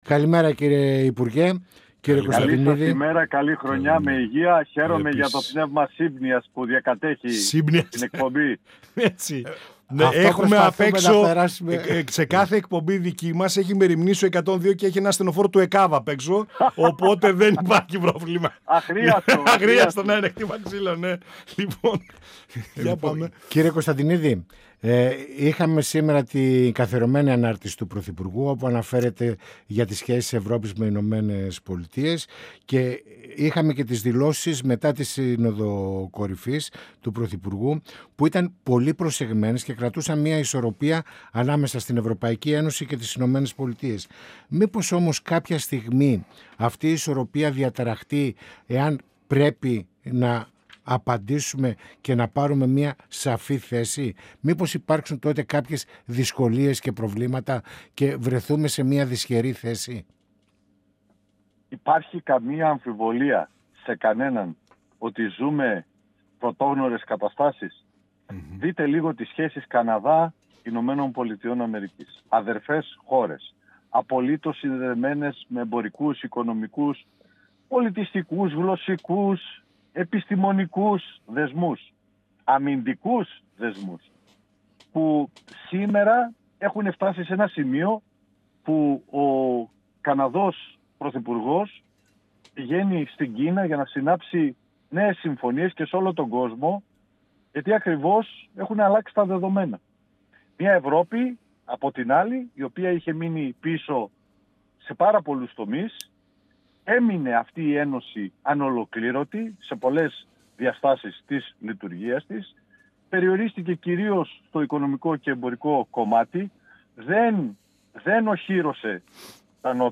Στις πολιτικές εξελίξεις, στα εθνικά θέματα και τις σχέσεις με την την Τουρκία, στην τροπολογία για την συν-επιμέλεια των τέκνων, που χαρακτηρίστηκε «φωτογραφική» αναφέρθηκε ο Βουλευτής της ΝΔ και π. υφυπουργός Εσωτερικών-αρμόδιος για θέματα Μακεδονίας – Θράκης Στάθης Κωνσταντινίδης , μιλώντας στην εκπομπή «Πανόραμα Επικαιρότητας» του 102FM της ΕΡΤ3.
Συνεντεύξεις